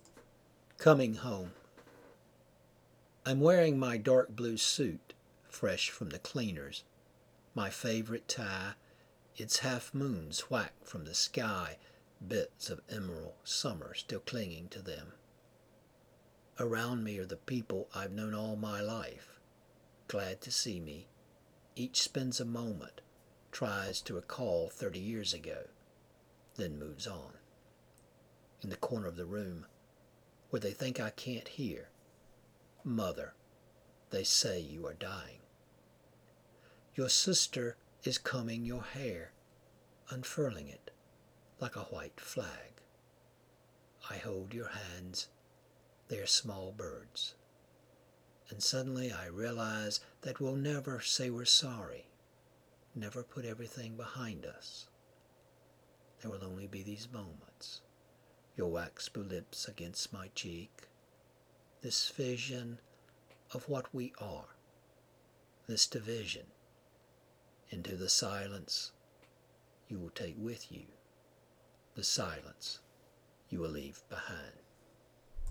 Coming Home (Reading)
Coming Home} for a reading of this poem.